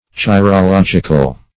Chirological \Chi`ro*log"ic*al\, a.